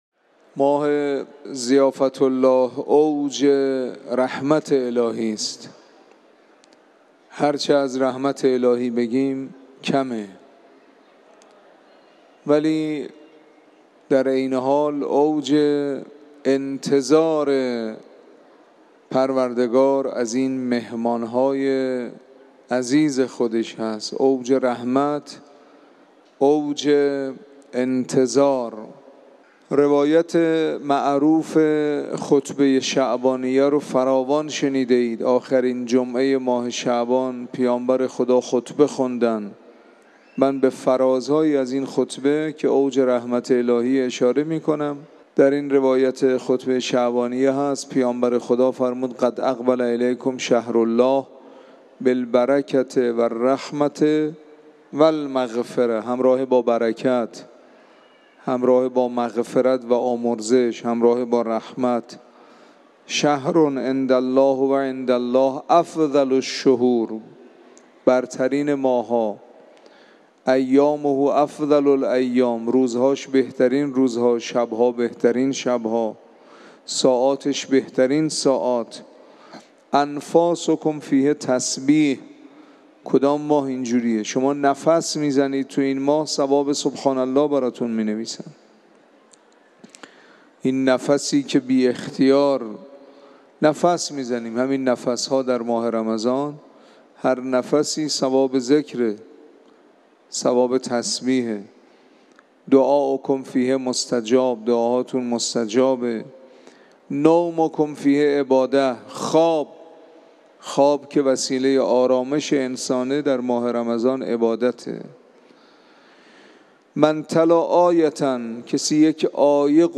فرازی از سخنان